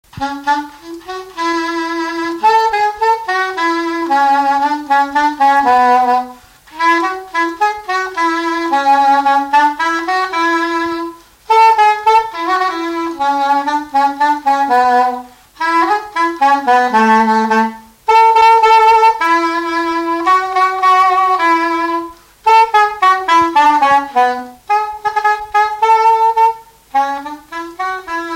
Avant-deux joué uniquement lors du bal des conscrits à Mardi-Gras
danse : quadrille : avant-deux
Pièce musicale inédite